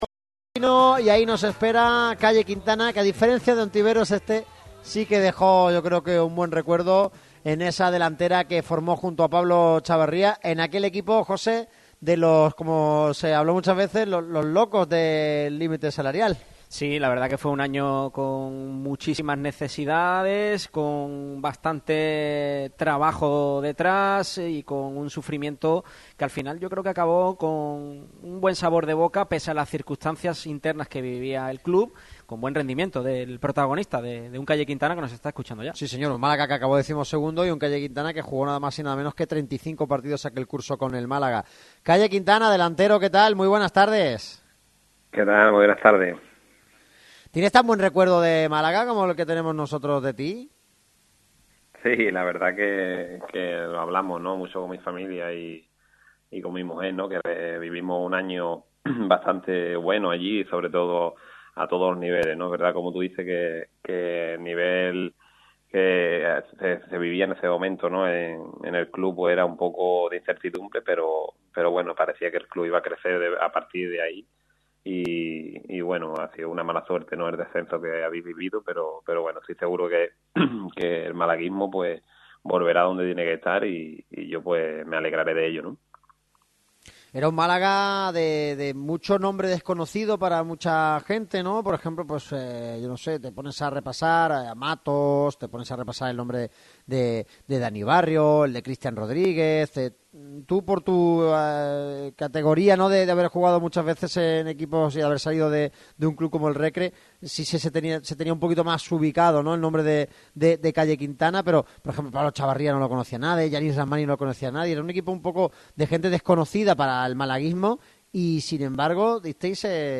El ex delantero malaguista ha atendido al micrófono rojo de Radio MARCA Málaga en la previa del duelo que enfrentará al cojunto boquerón y a su actual club,